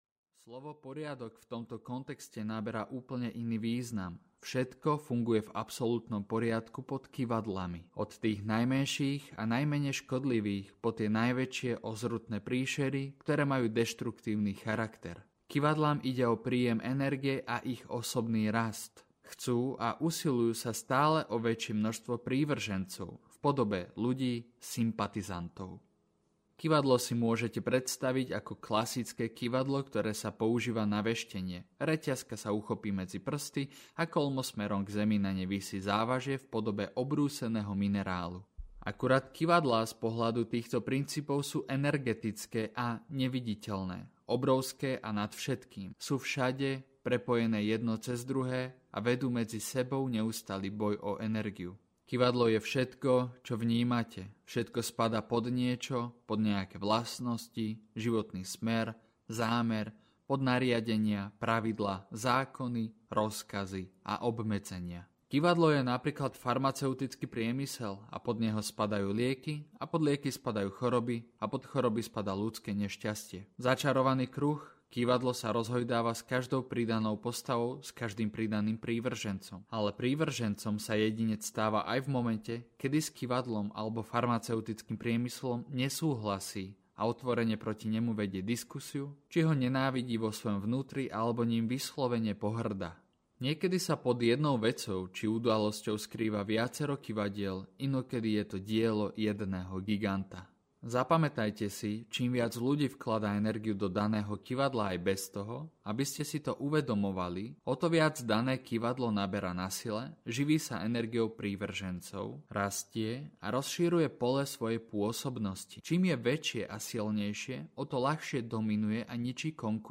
V okovách systému audiokniha
Ukázka z knihy